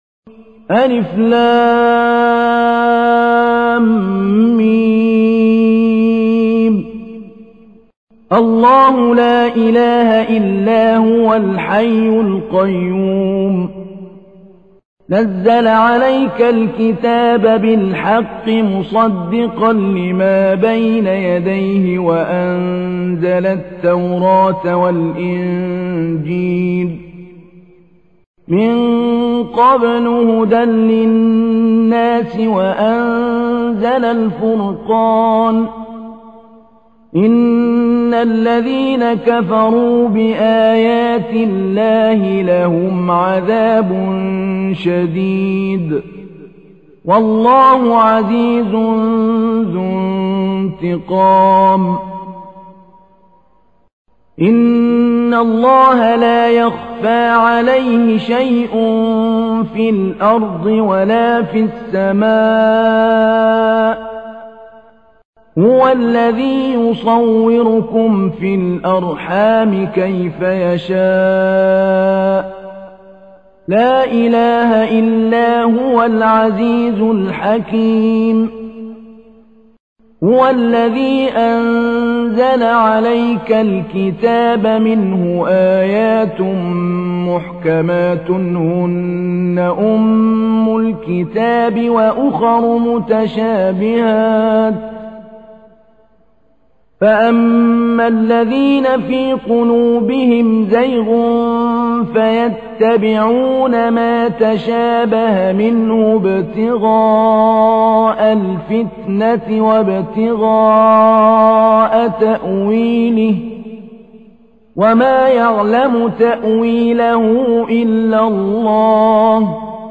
تحميل : 3. سورة آل عمران / القارئ محمود علي البنا / القرآن الكريم / موقع يا حسين